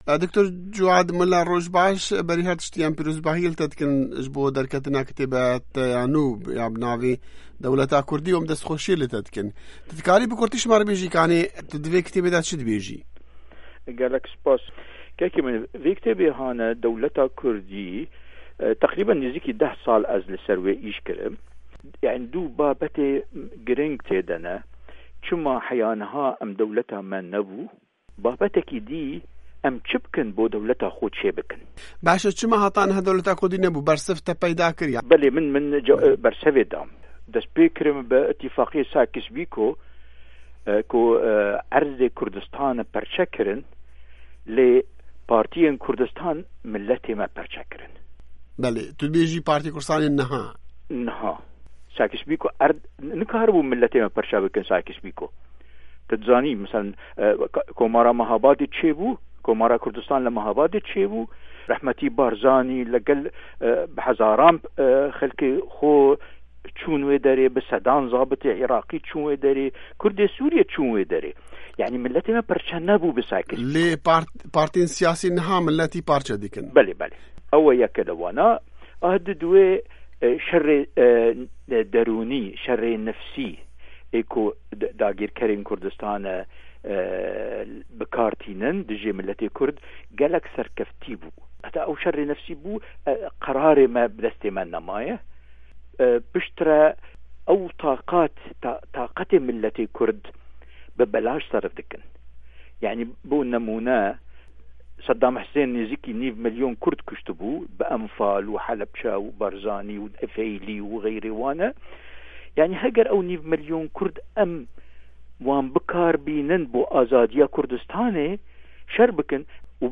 Di hevpeyvînekê de